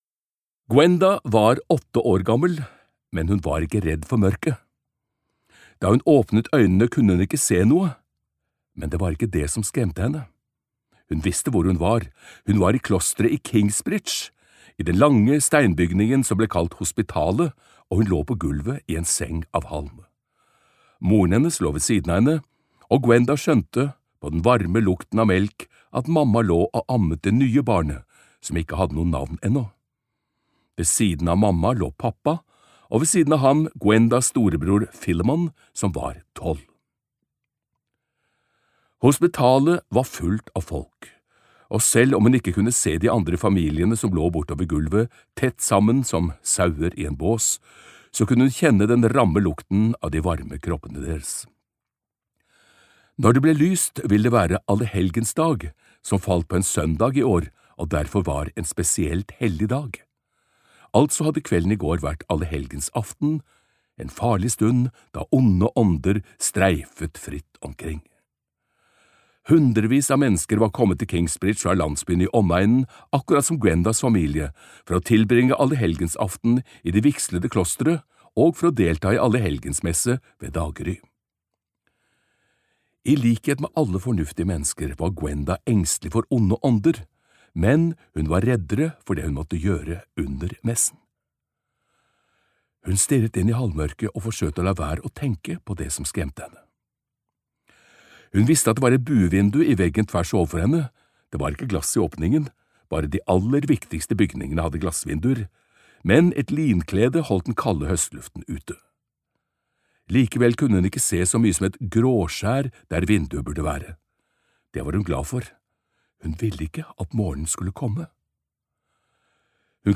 I all evighet - Del 1 (lydbok) av Ken Follett